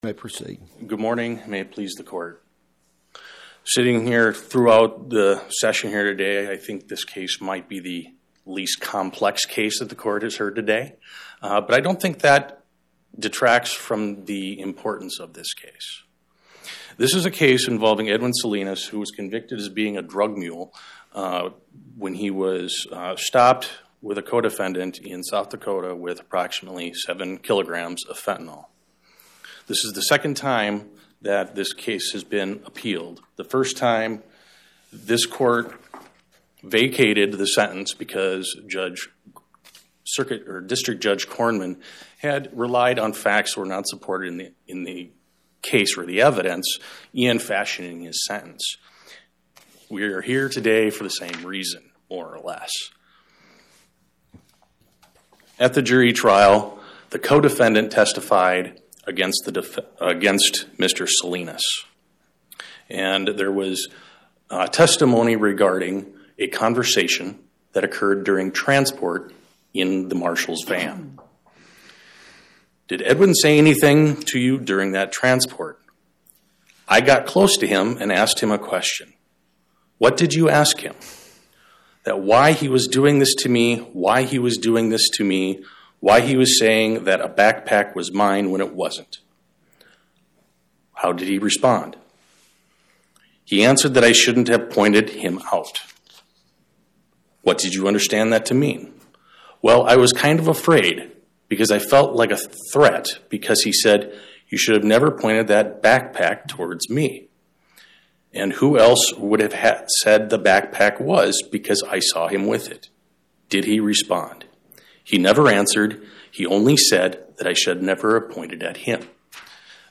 Oral argument argued before the Eighth Circuit U.S. Court of Appeals on or about 03/19/2026